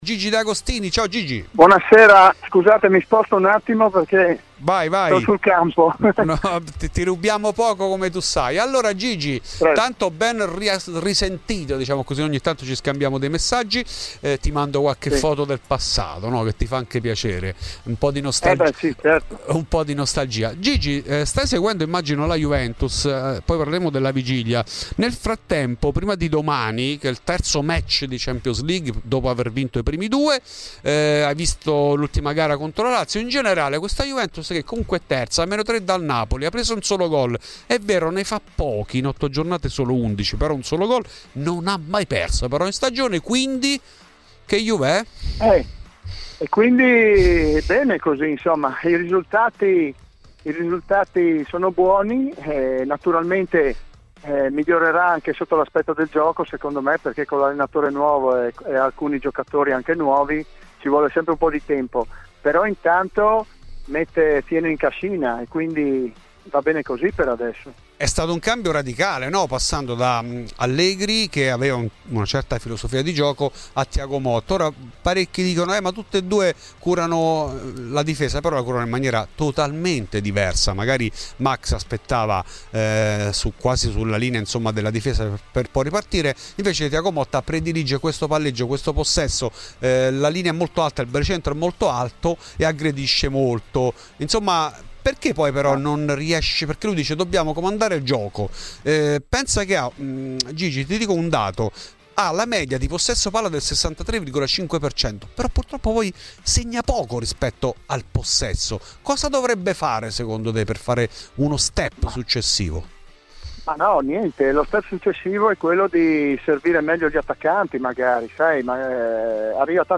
Rispetto ai singoli, stenta a decollare Douglas Luiz, l'unico acquisto di Giuntoli al momento non all'altezza. In ESCLUSIVA a Fuori di Juve Gigi De Agostini, ex difensore bianconero che ha detto la sua anche sulla corsa scudetto.
Nel podcast l'intervento integrale